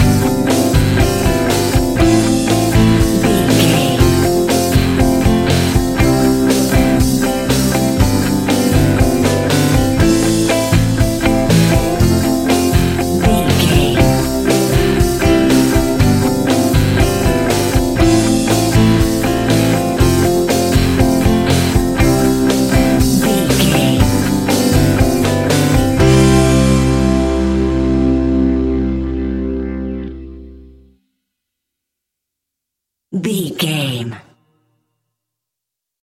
Ionian/Major
fun
energetic
uplifting
cheesy
instrumentals
guitars
bass
drums
piano
organ